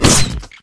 ImpHit.ogg